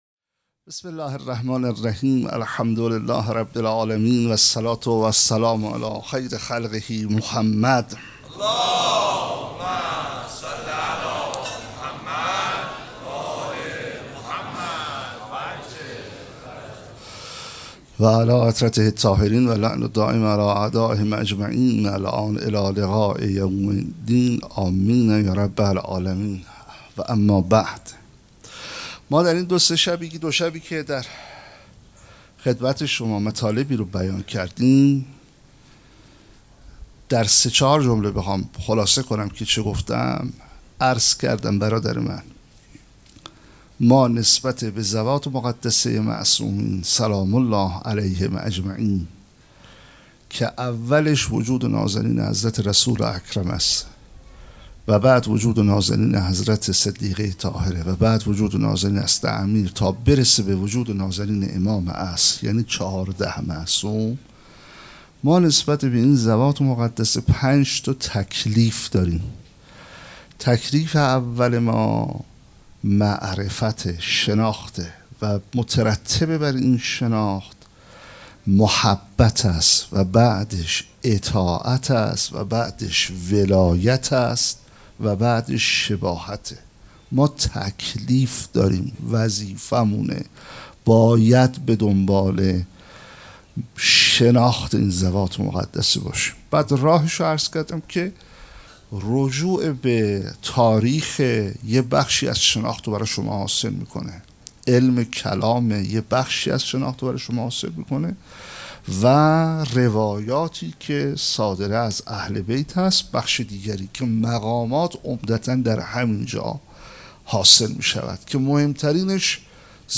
سخنرانی
شب سوم مراسم عزاداری فاطمیه دوم ۱۴۴۴دوشنبه ۵ دی ۱۴۰۱ | ۲ جمادی الثانی ۱۴۴۴‌‌‌‌‌‌‌‌‌‌‌‌‌هیئت ریحانه الحسین سلام الله علیها